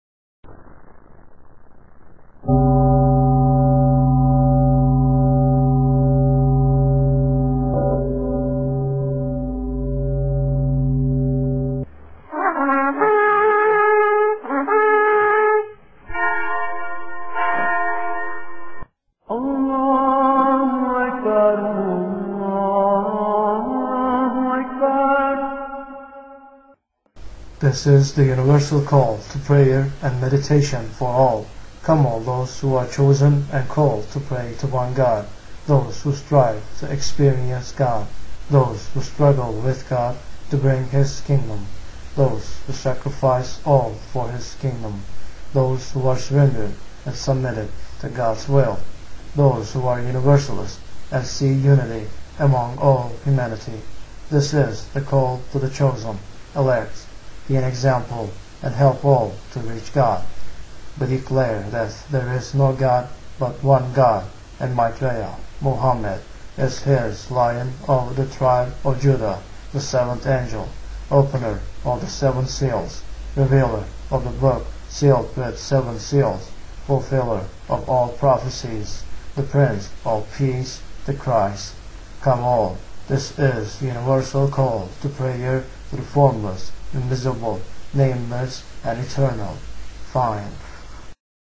Now we have decided to make this call more universal and use an audio which combines sounds from the four major religions on earth (Mystical Paths, Judaism, Christianity and Islam) to Call to Prayer.
2), we read the words we now have in the Mission as the "Universal Call to Prayer."